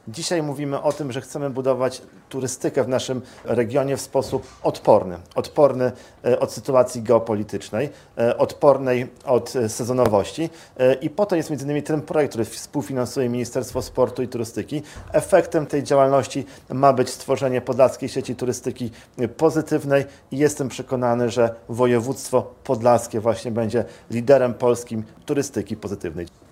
Marszałek województwa podlaskiego Łukasz Prokorym mówił, jak projekt wpłynie na rozwój turystyki w regionie.